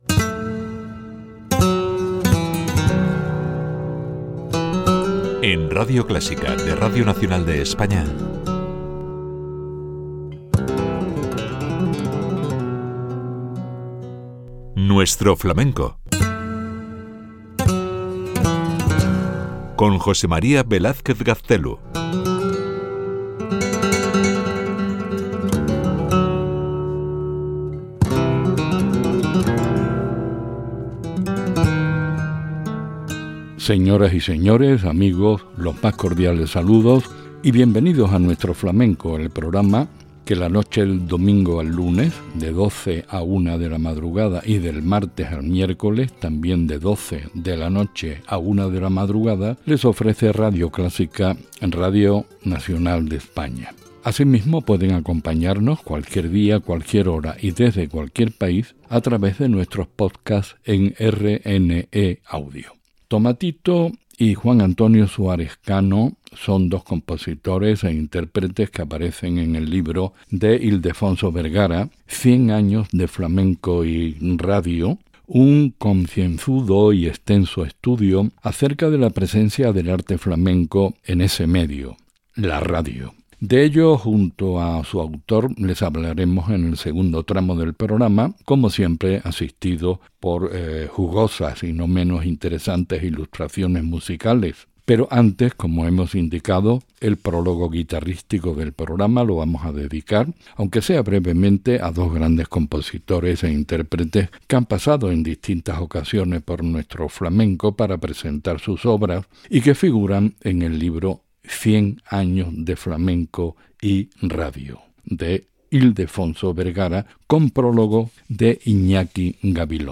Careta del programa, hores d'emissió, el llibre "100 años de flamenco y radio", tres temes de guitarra flamenca, indicatiu del programa i tema musical
Musical